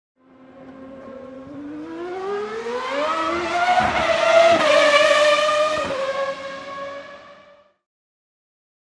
Звуки Формулы-1
На этой странице собраны лучшие звуки Формулы-1: от оглушительного рева гибридных двигателей до свиста ветра на прямых.
Гонки машин по трассе